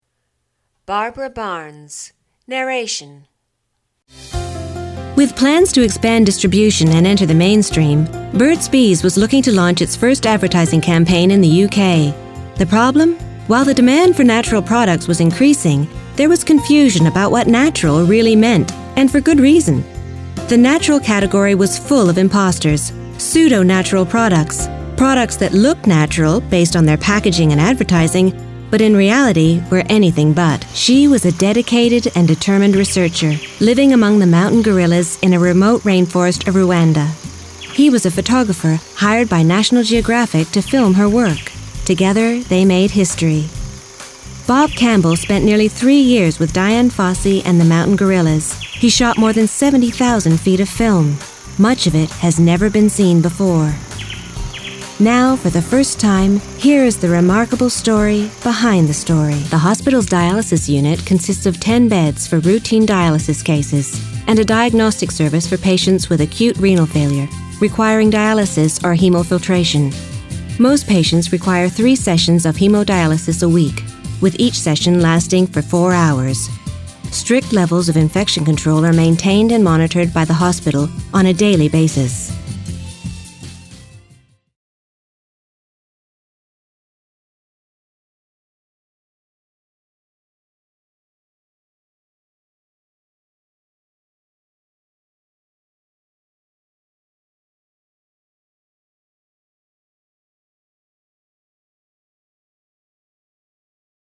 40/50's North American, Versatile/Reassuring/Natural
Narrative/Documentary